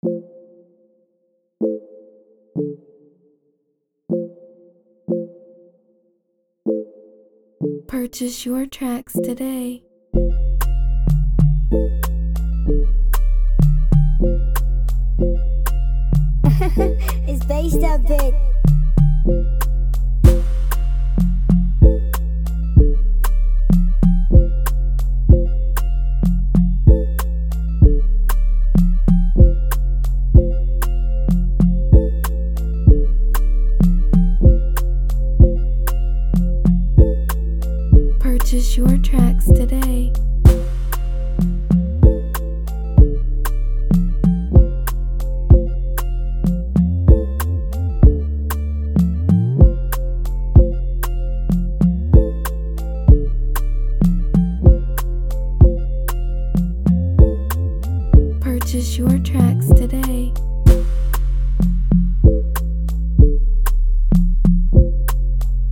a fine free afrobeat instrumental